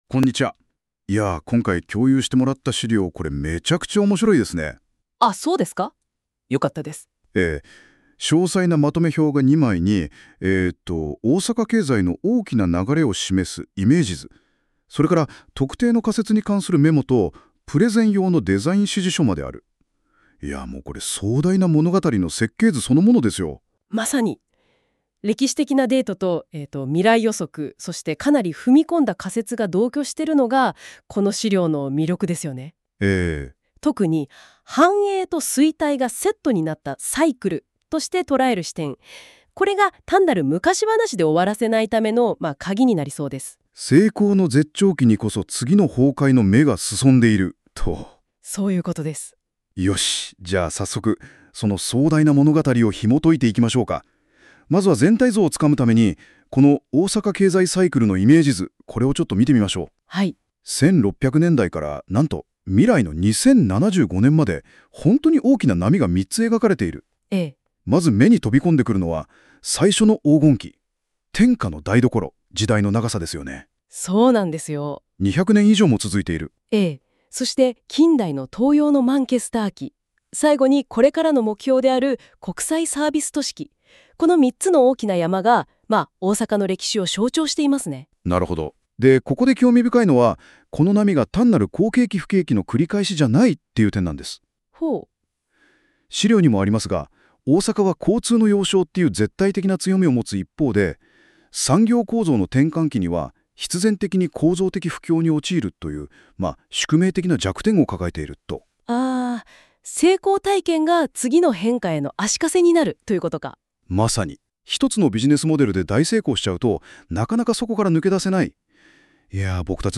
音声解説（NotebookLM作成）
音声解説（NotebookLM作成） ※読みがおかしい箇所が多々ありますが、AI出力のためご容赦ください。